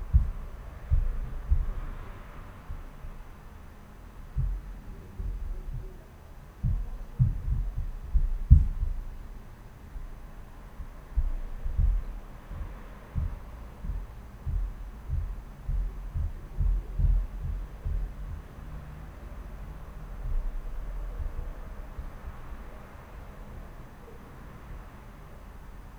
Die laute Landshuter Allee sorgt für ein deutlich hörbares Grundrauschen. Es hat denselben Peak wie in den oberen Beispielen.